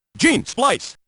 Unused voices
An old clip originally used in X-Men: Children of the Atom and then re-used in X-Men Vs. Street Fighter.